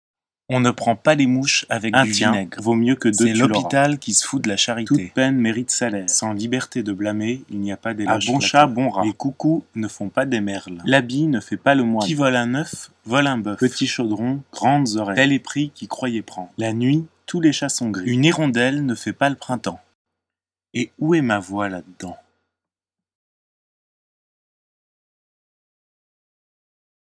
Poésie sonore